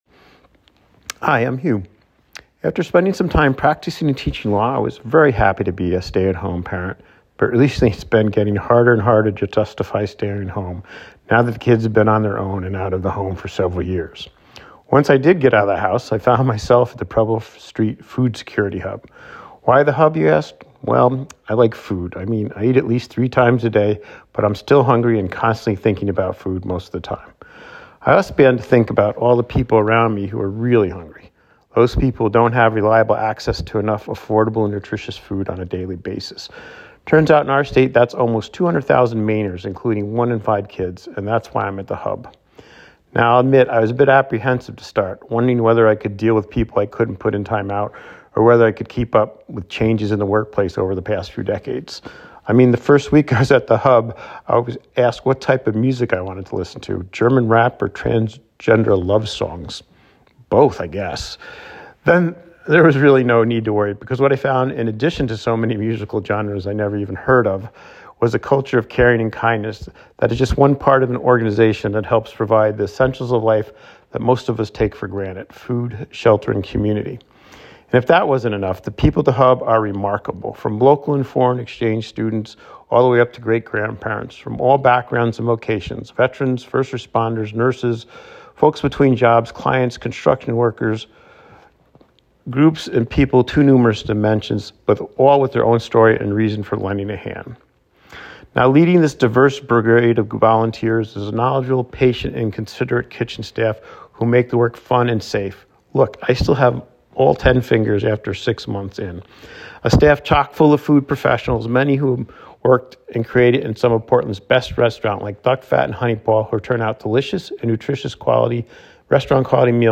Hear a regular volunteer share why they volunteer and what their volunteer experience is like.